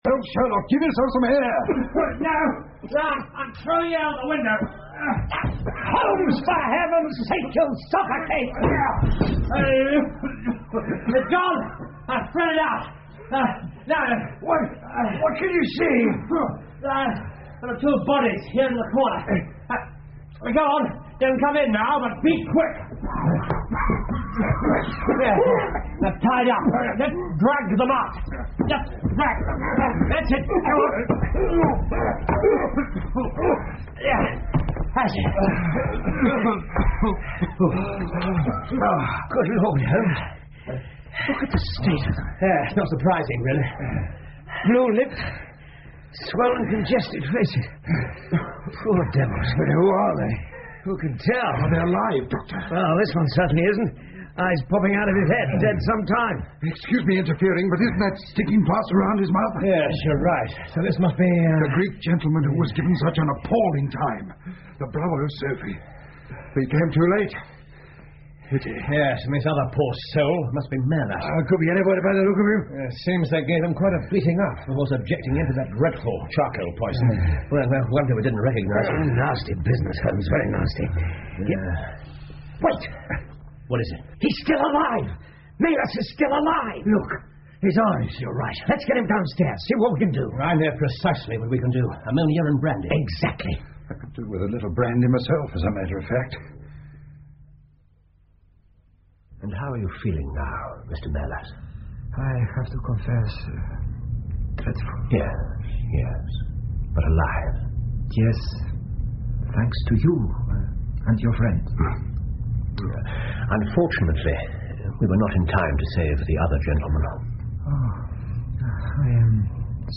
福尔摩斯广播剧 The Greek Interpreter 8 听力文件下载—在线英语听力室